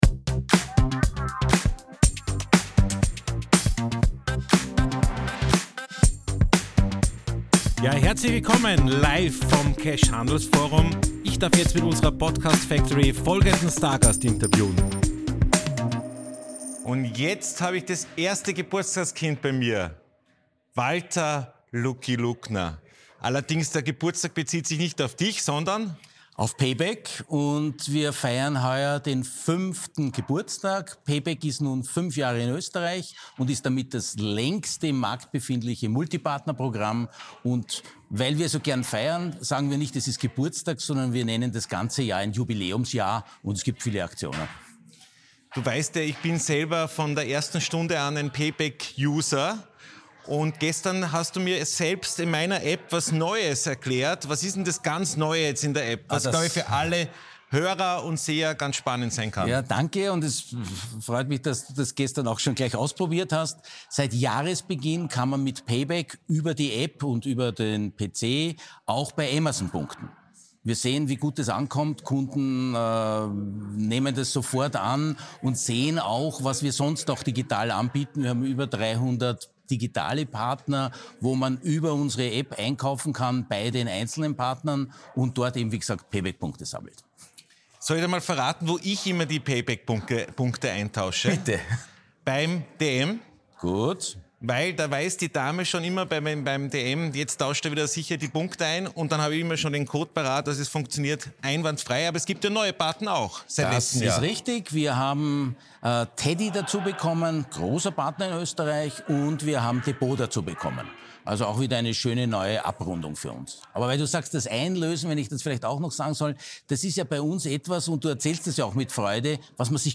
Live vom CASH Handelsforum 2023 in Zusammenarbeit mit ProSiebenSat.1 PULS 4